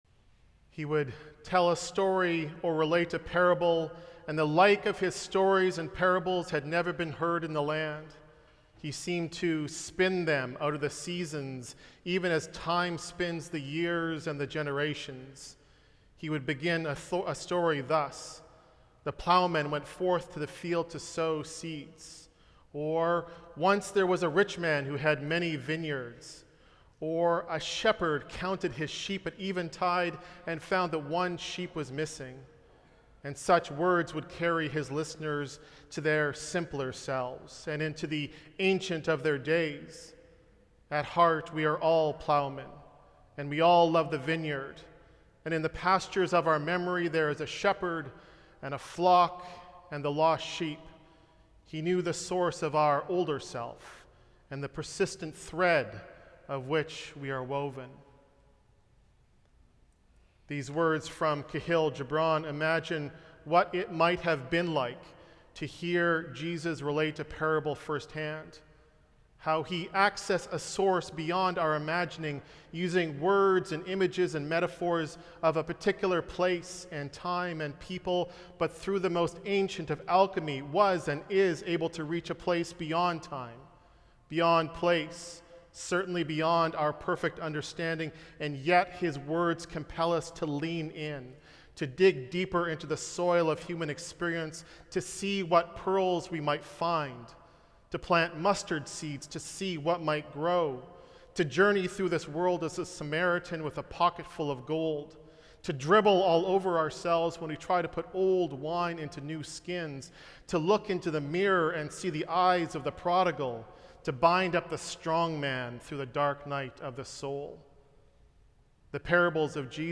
Sermon Notes